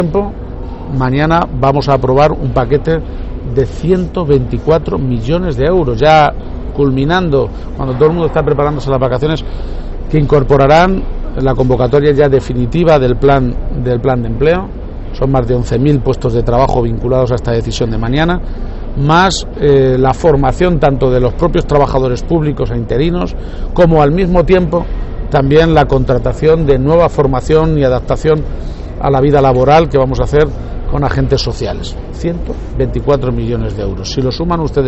Presidente Lunes, 25 Julio 2022 - 12:45pm García-Page ha explicado hoy en Albacete, en declaraciones a los medios de comunicación, que el Consejo de Gobierno que se celebra mañana aprobará una nueva batería de medidas destinadas a la mejora de la cualificación y la empleabilidad de las personas ocupadas y desempleadas de la región, con una inversión de 124 millones y 11.000 puestos de trabajo vinculados a este plan. garcia-page_albacete250722_124_millones_empleo.mp3 Descargar: Descargar